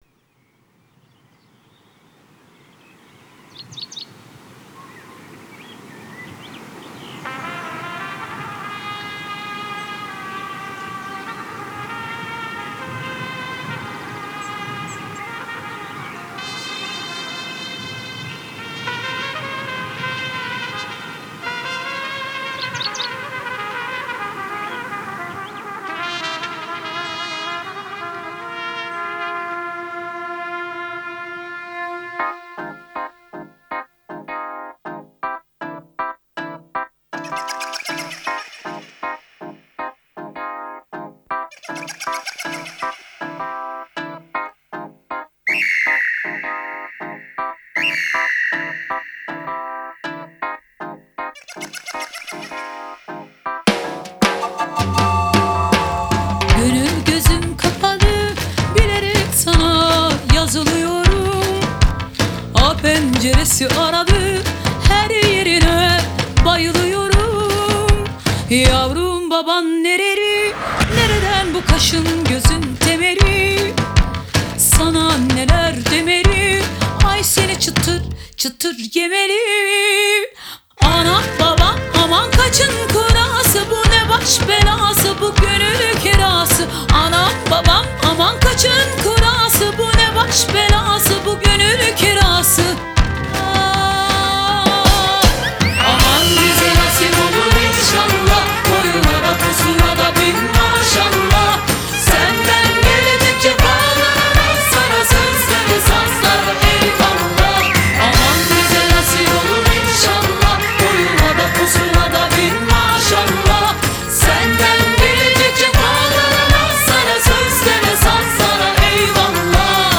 آهنگ ترکیه ای آهنگ شاد ترکیه ای آهنگ نوستالژی ترکیه ای